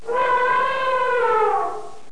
c_elep_atk2.wav